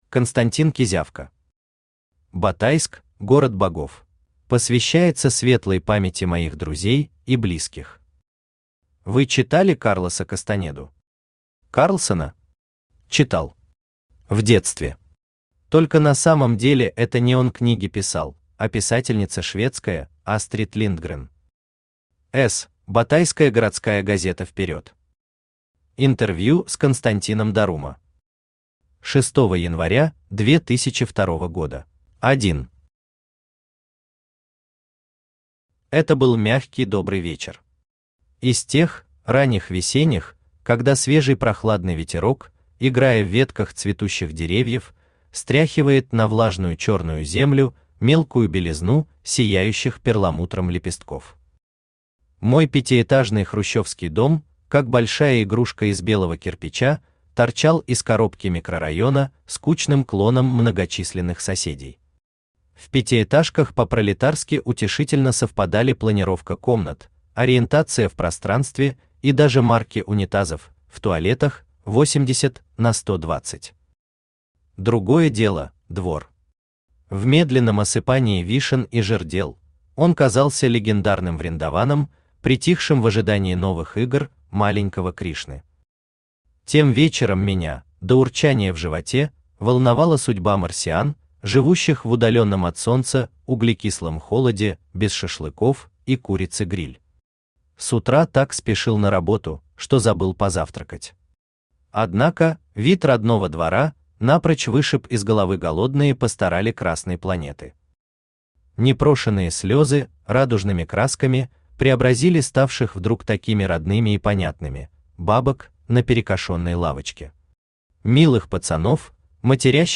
Аудиокнига Батайск – город богов | Библиотека аудиокниг
Aудиокнига Батайск – город богов Автор Константин Иванович Кизявка Читает аудиокнигу Авточтец ЛитРес.